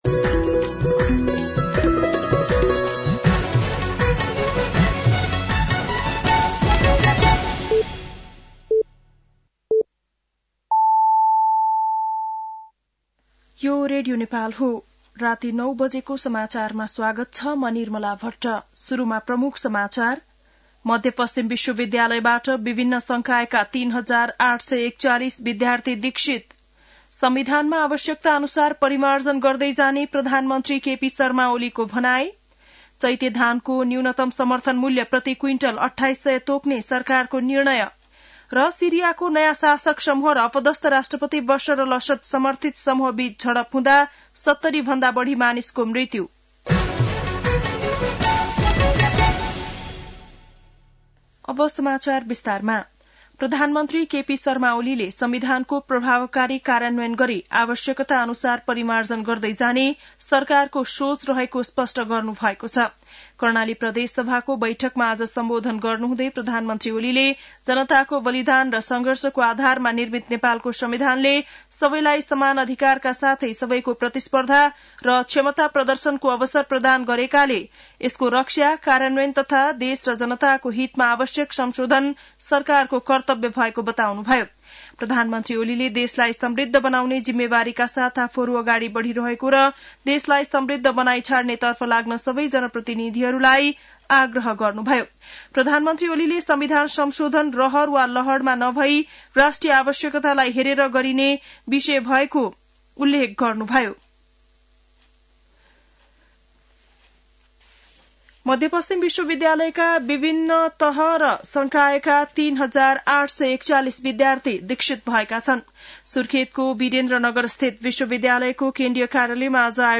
बेलुकी ९ बजेको नेपाली समाचार : २४ फागुन , २०८१